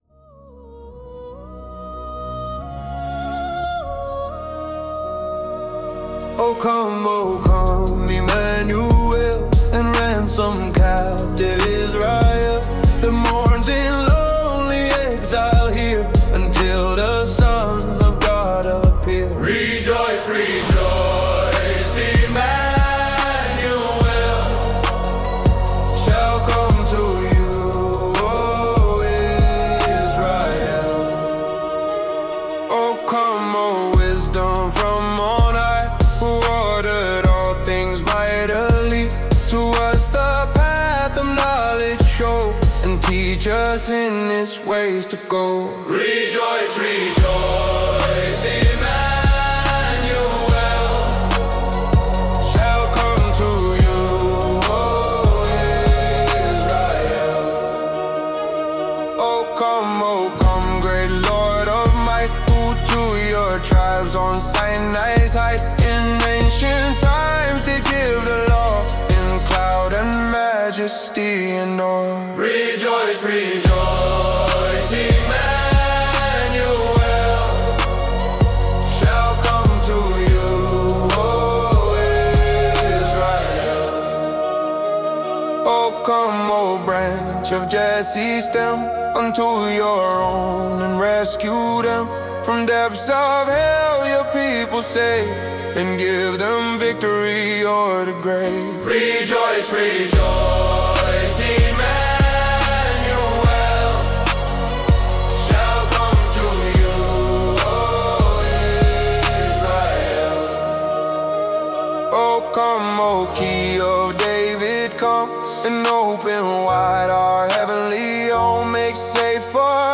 Rap version of the traditional hymn